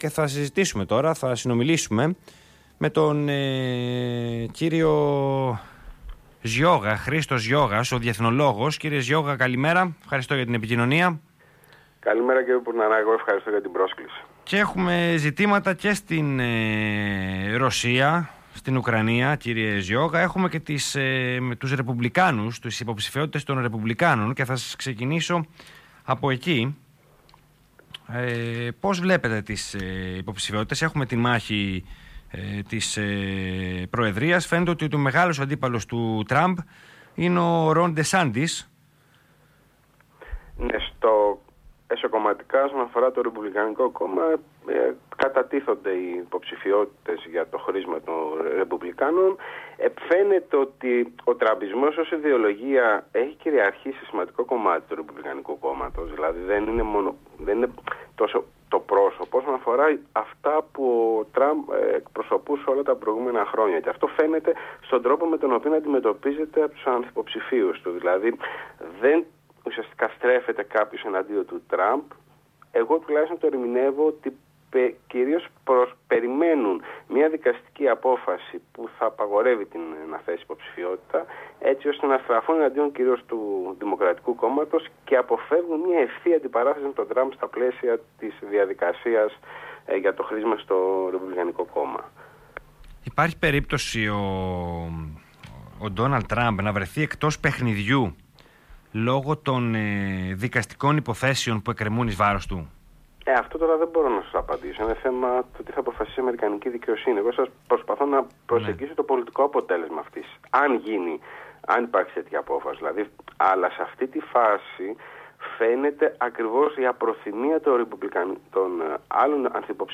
Συνέντευξη παραχώρησε στον Ελλάδα 94,3 FM